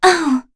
Kara-Vox_Damage_01.wav